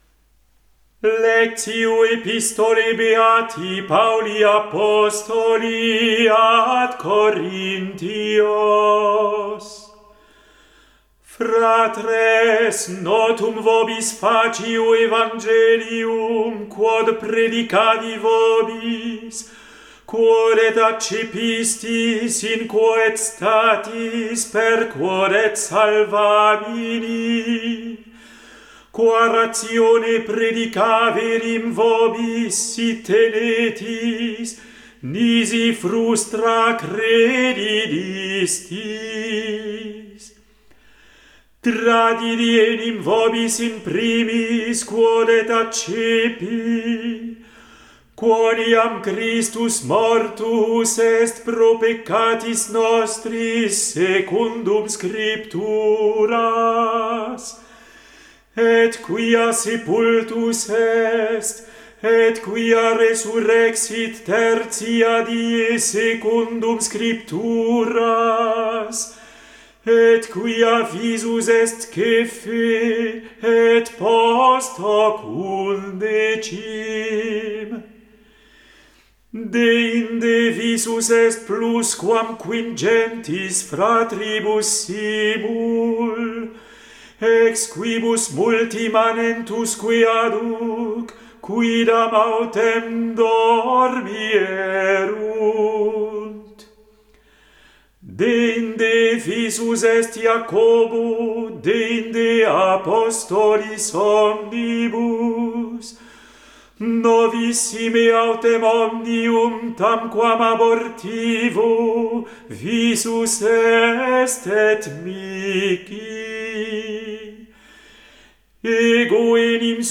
Epistola